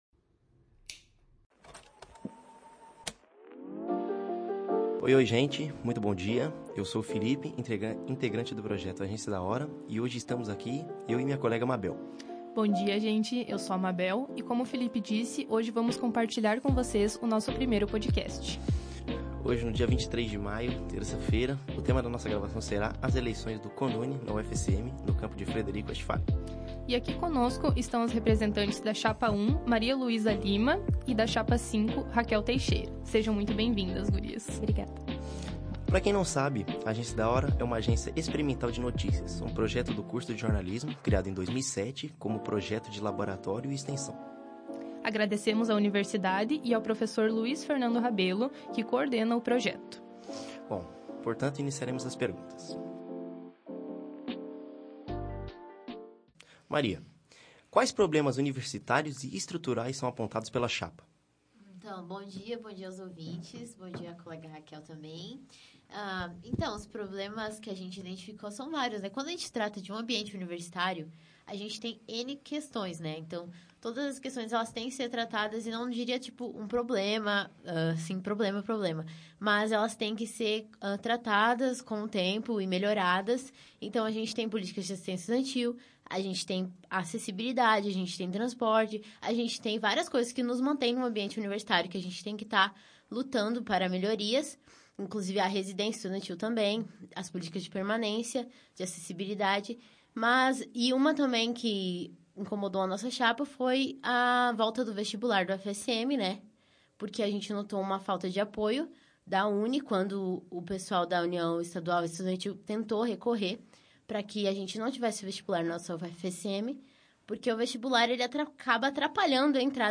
Gravação de podcast é realizada com as chapas do campus de Frederico Westphalen